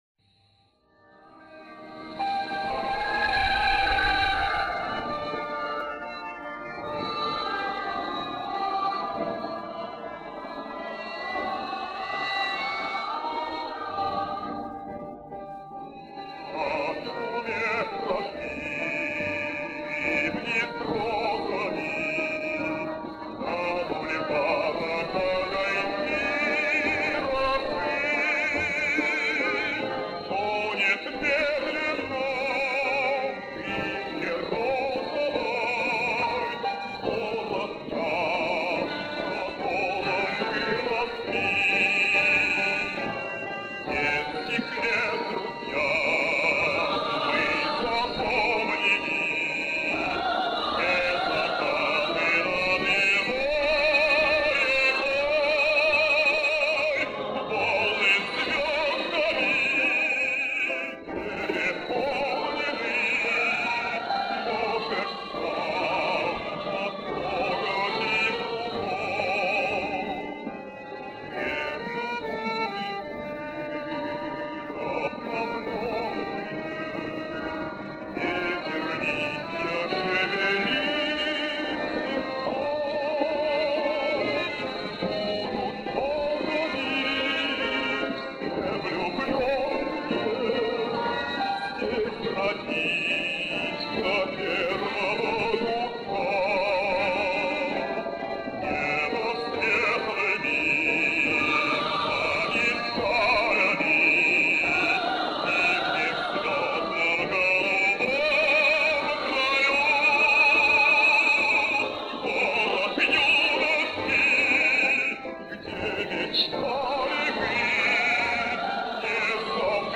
Описание: Улучшение качества.